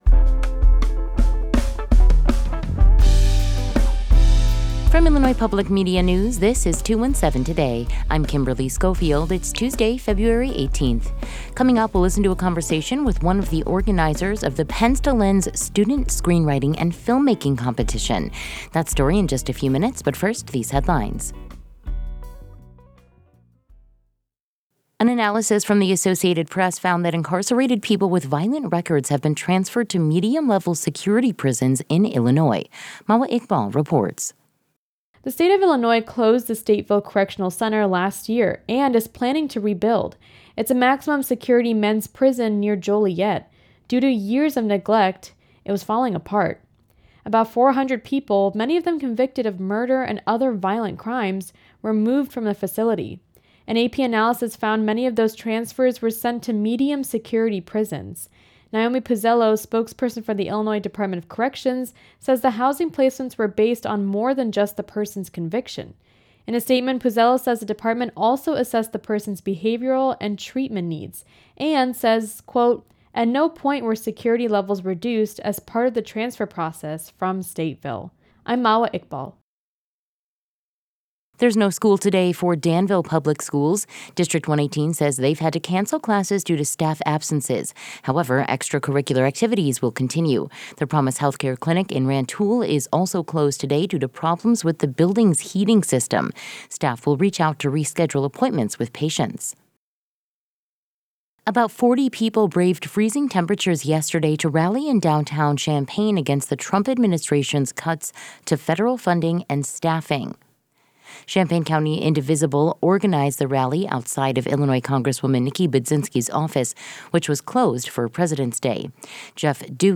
In today's deep dive, we'll listen to a conversation with one of the organizers the Pens to Lens Student Screenwriting and Filmmaking Competition.